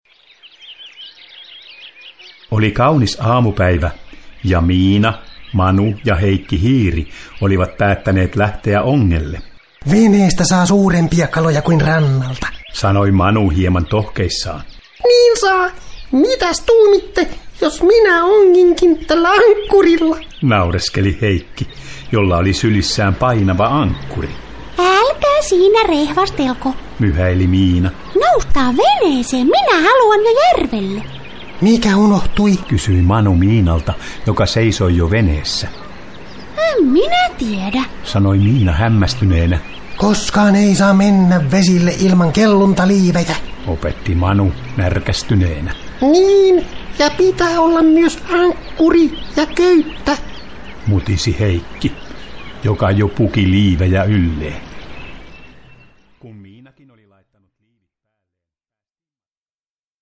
Miina ja Manu vesillä – Ljudbok – Laddas ner